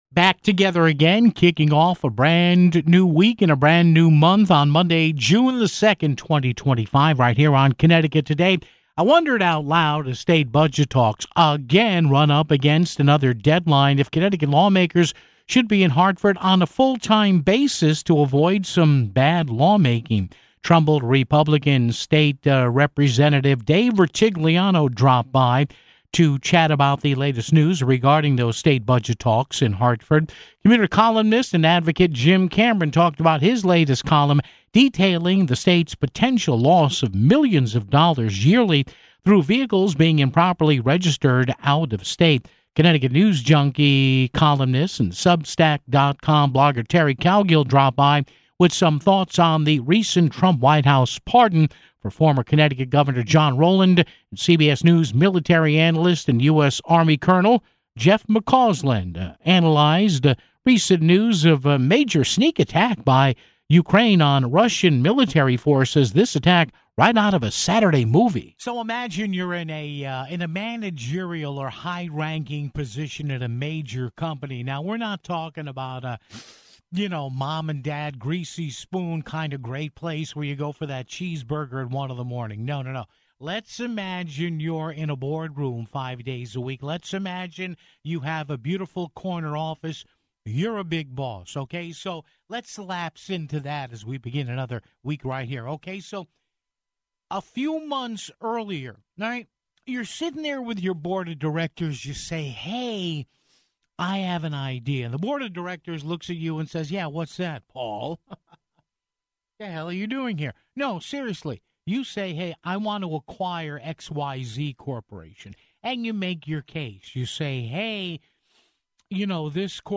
Trumbull GOP State Rep. Dave Rutigliano dropped by to chat about the latest news regarding state budget talks in Hartford (14:47).